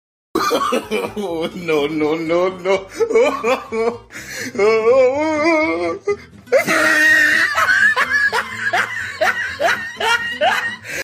Oh no no Laugh Meme sound effects free download